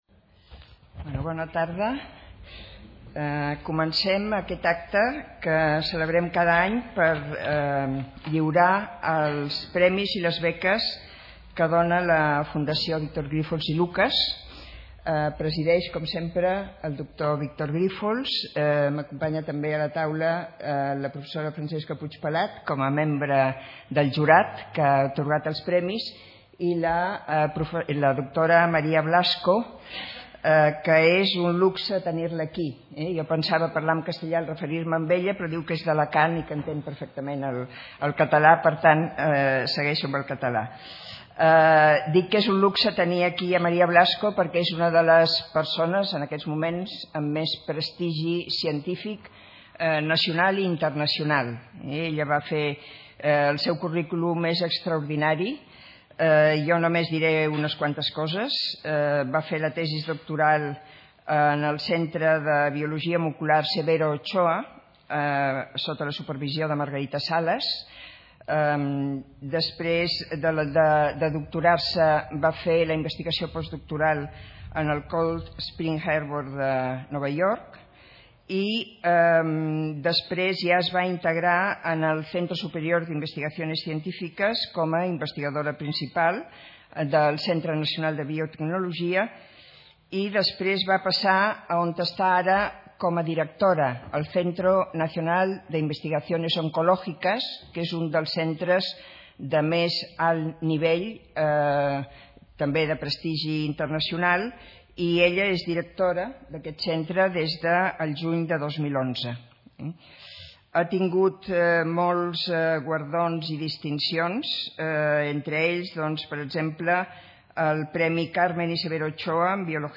The Awards and Grants Ceremony 2011-12 on YouTube
Video Awards Ceremony Video María Blasco Lecture Audio of the full conference (Languege: Spanish.